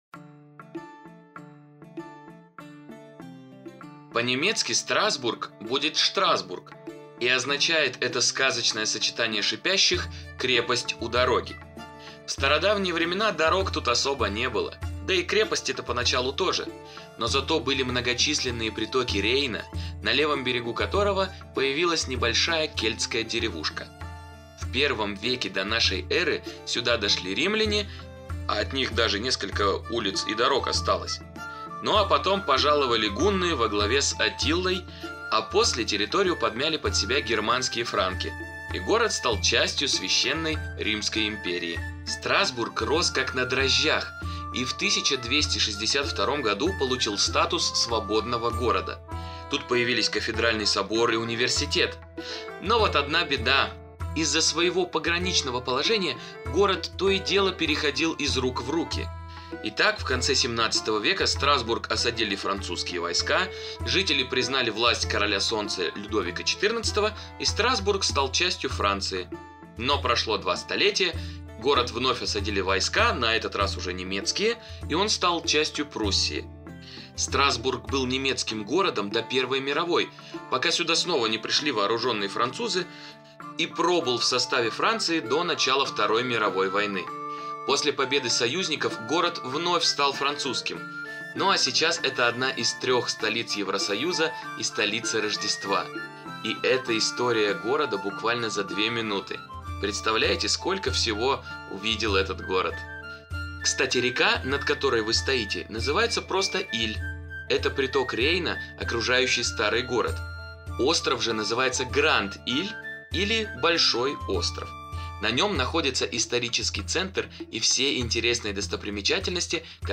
Страсбург за 1 день с аудиогидом на русском от TouringBee